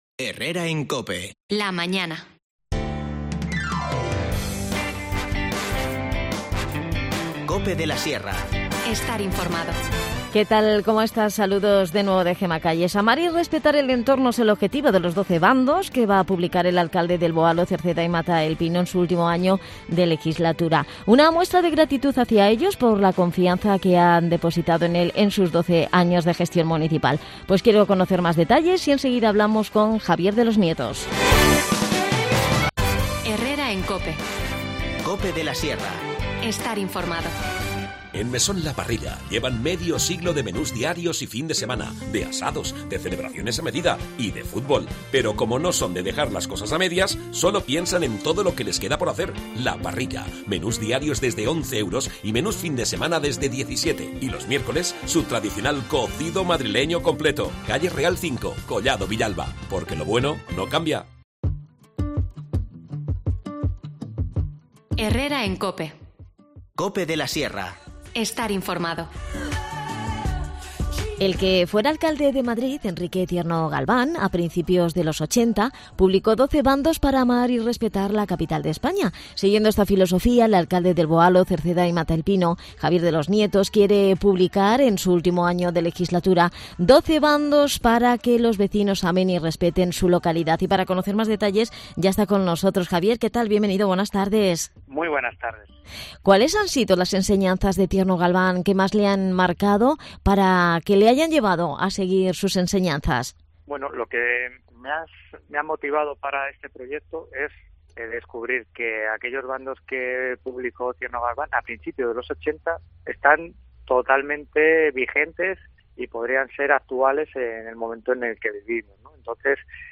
Javier de los Nietos, nos cuenta más detalles.
Las desconexiones locales son espacios de 10 minutos de duración que se emiten en COPE, de lunes a viernes.